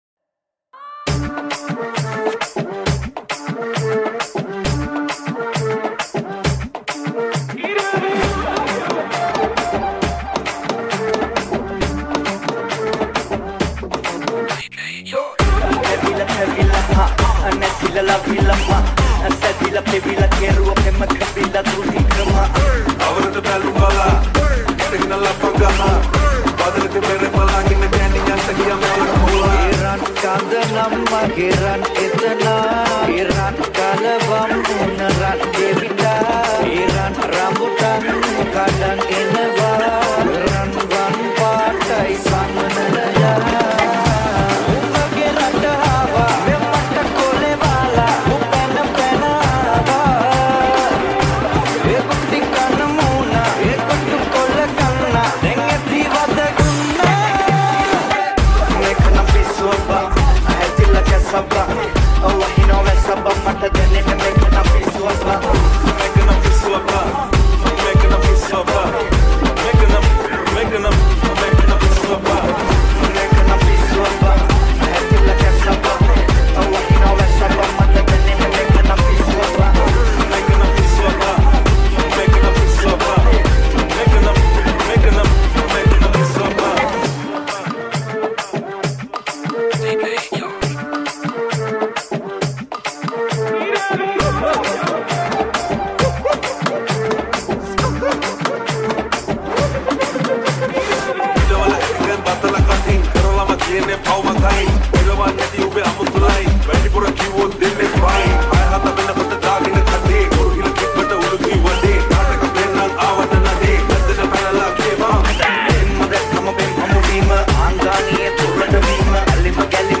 6 8 Punch Remix